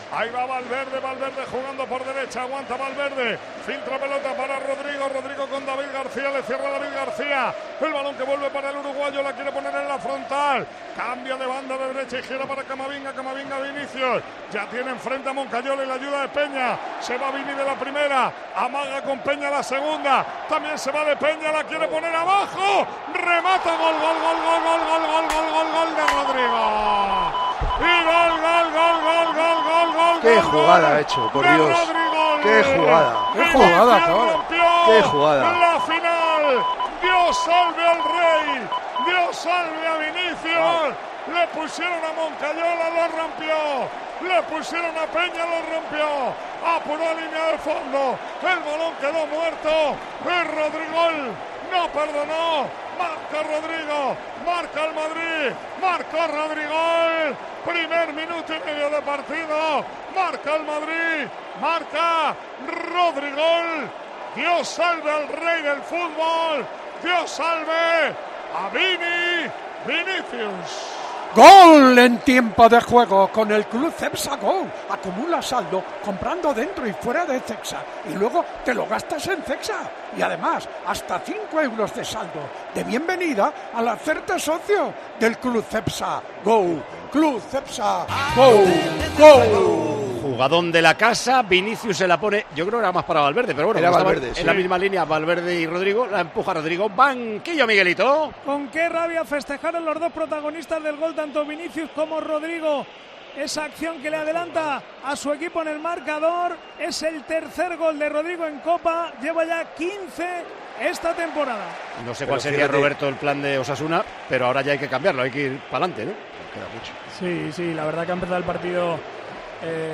El narrador de la final de la Copa del Rey en Tiempo de Juego tuvo palabras de alabanza hacia el jugador brasileño tras su gran jugada en el primer gol blanco.
Un gran primer gol al que Manolo Lama se rindió durante la narración en 'Tiempo de Juego': "Dios salve al rey del fútbol".